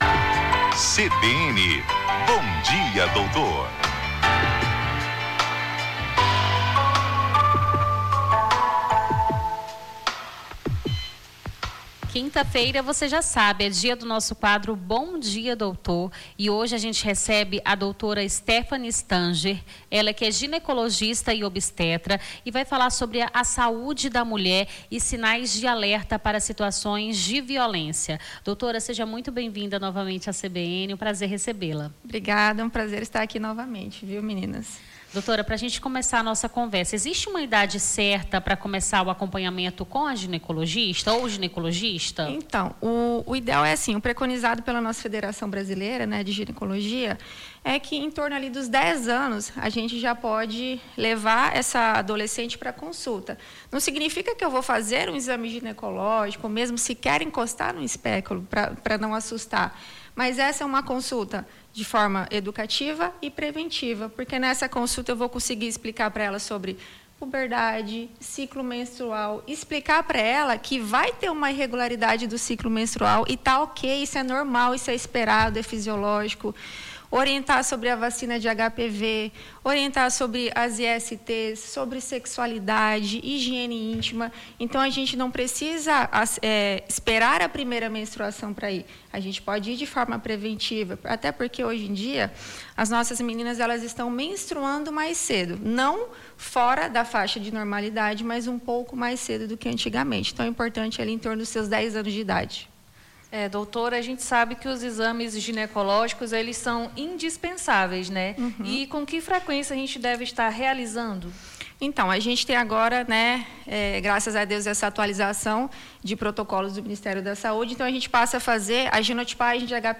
Na manhã desta quinta-feira, 26, conversamos com a doutora